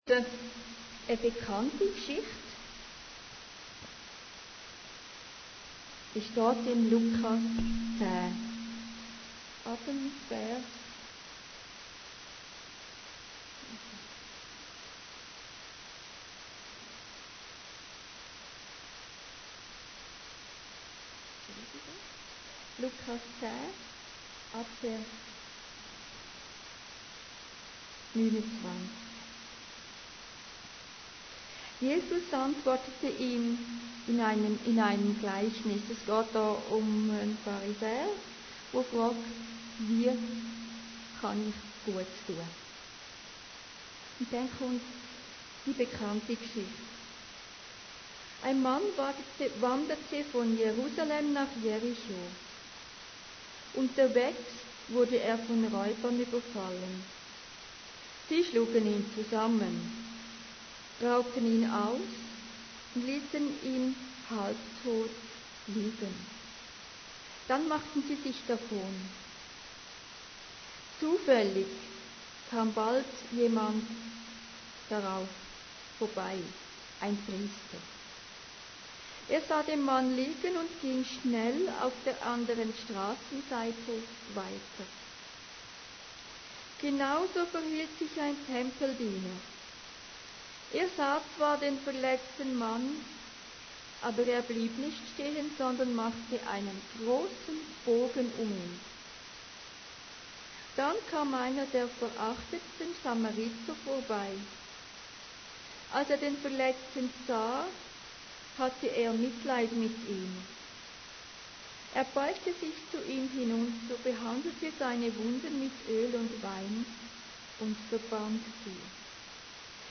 Predigten Heilsarmee Aargau Süd – Barmherziger Samariter